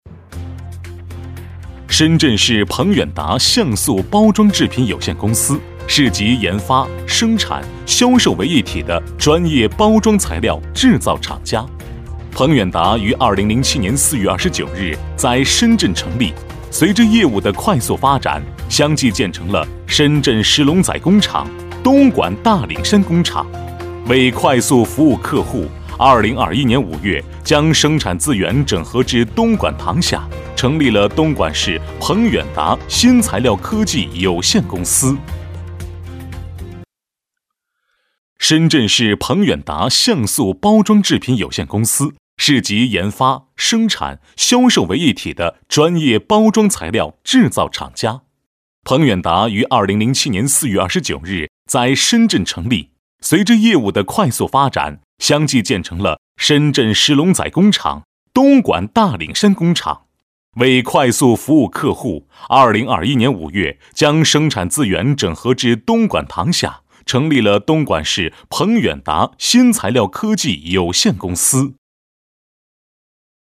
中文男声
类型: 宣传片 风格: 大气 沉稳 价格